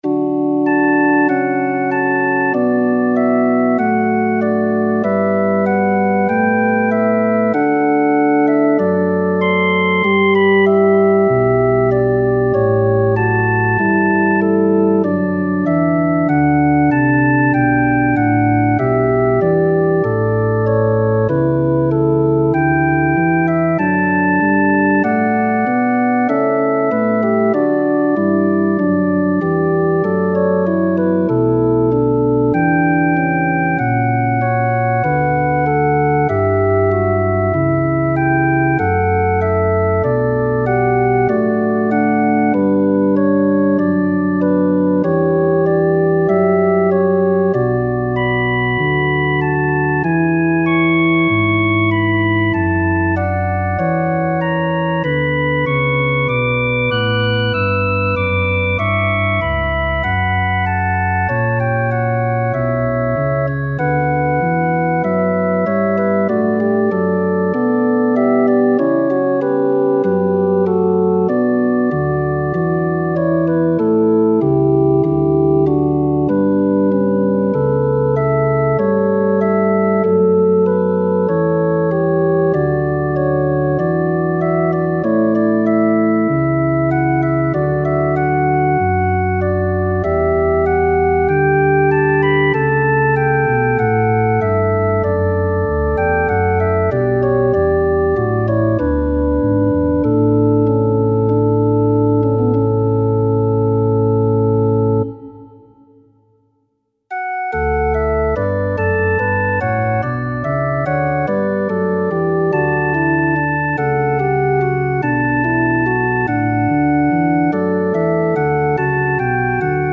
Vom Himmel hoch, ihr Englein kommt (Paderborn, 1617) Entgegen der Textaussage wird hier nicht mit großem Orchester „gepfeift und getrombt”, die intimen Töne eines Orgeltrios (inklusive Vorspiel) lassen diese Melodie in einem meditativen „Licht” erklingen – schließlich soll das Kind dabei schlafen können.